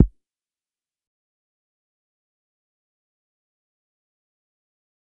Metro Kick 5.wav